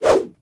footswing9.ogg